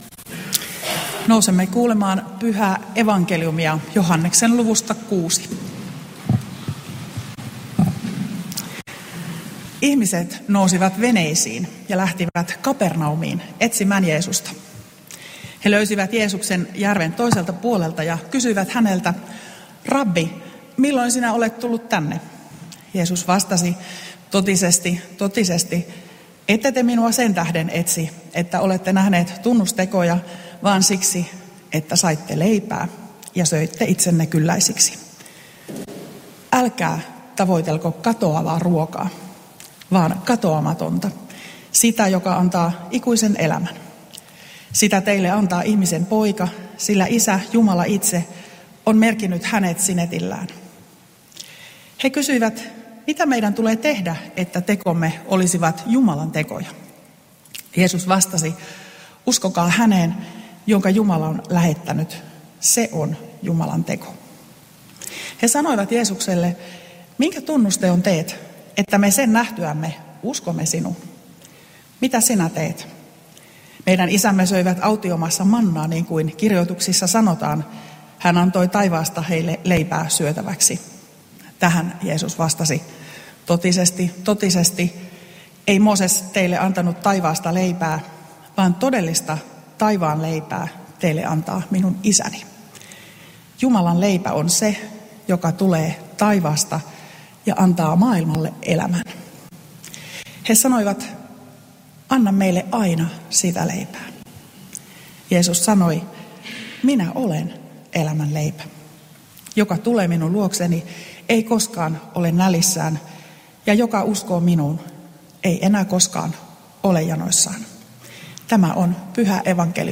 Kannus